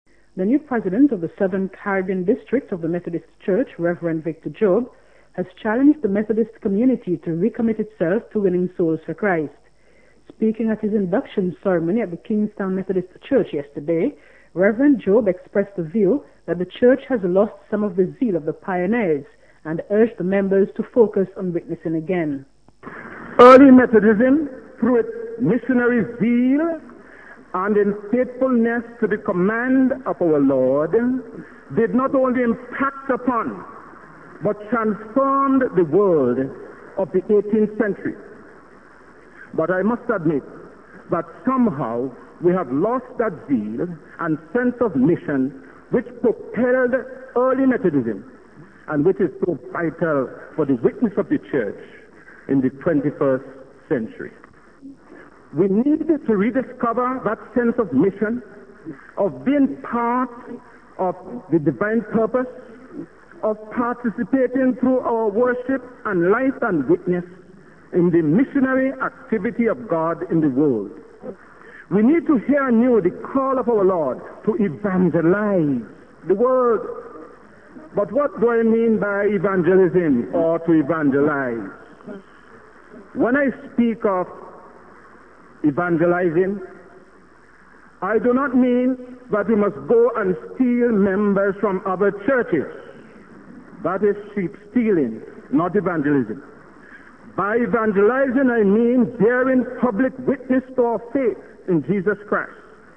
St Vincent and the Grenadines is another group of anglophone islands in the eastern Caribbean and varieties of English there share features with acrolectal varieties of English at other anglophone locations, e.g. r-lessness (this does not apply to Barbados, however), vowel monophthongs of mid length and syllable-timing. In the consonantal inventory one finds that the interdental fricatives /θ/ and /ð/ tend to be realised as stops, e.g. think [tɪŋk] and this [dɪs].
St_Vincent_Grenadines_Acrolectal.wav